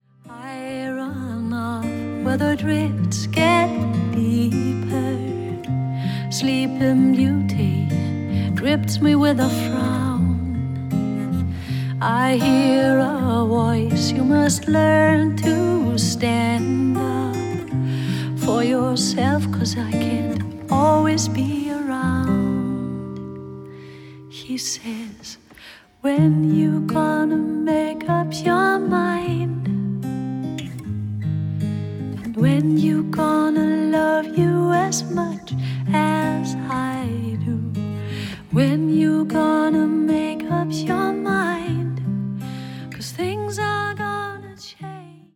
録音：2014年 ミュンヘン